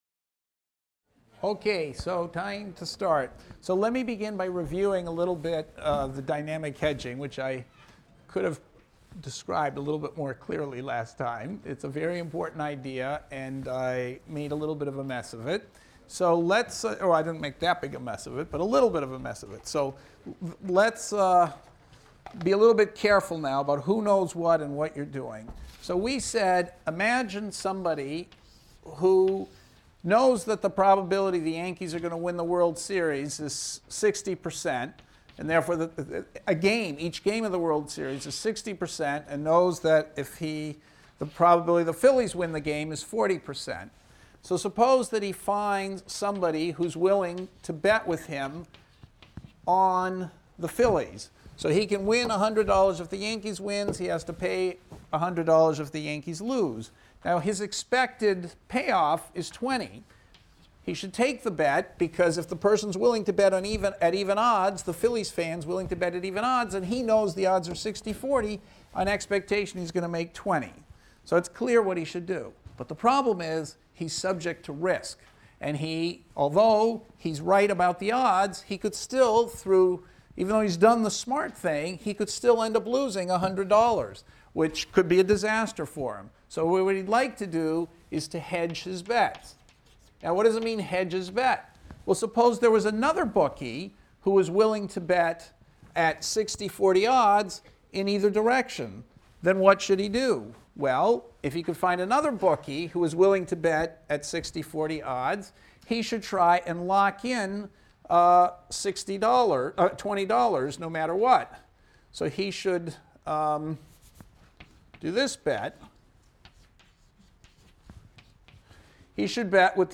ECON 251 - Lecture 21 - Dynamic Hedging and Average Life | Open Yale Courses